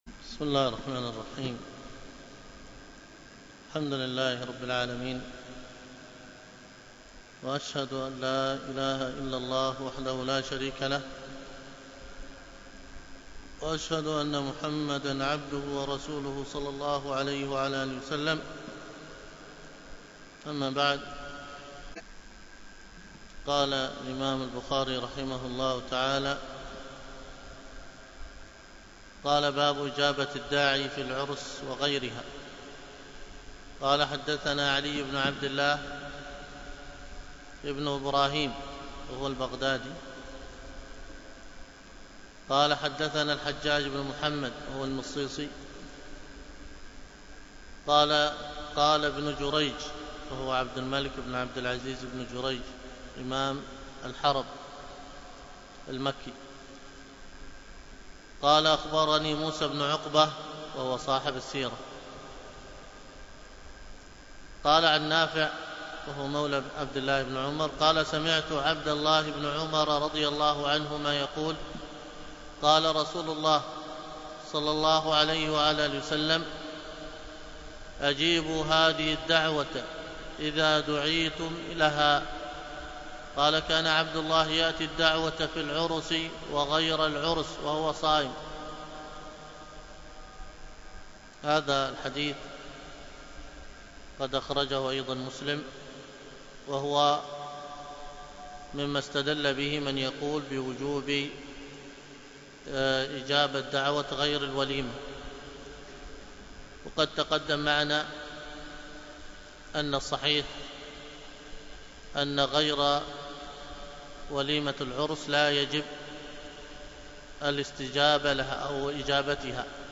الدروس الفقه وأصوله